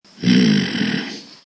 fx-hmm-male.mp3